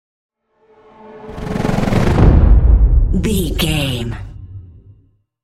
Chopper whoosh to hit large trailer
Sound Effects
Atonal
intense
tension
woosh to hit